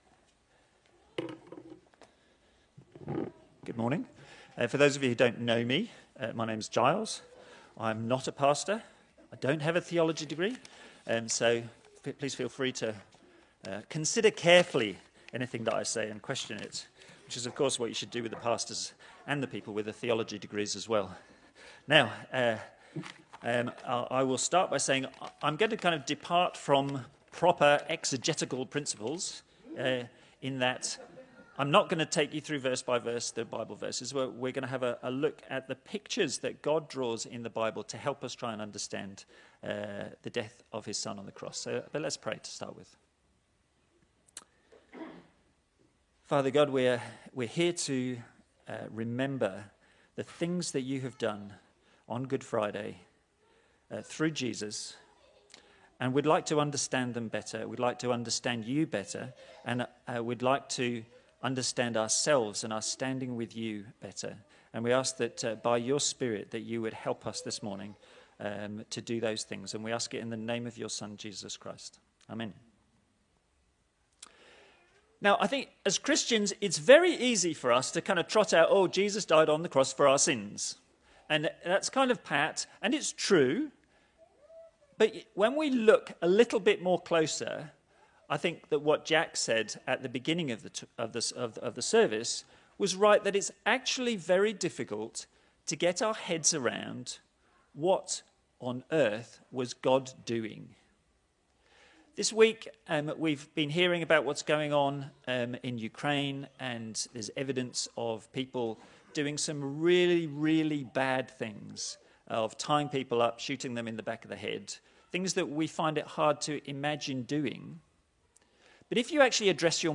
Exodus Passage: Exodus 12:1-14 Service Type: Good Friday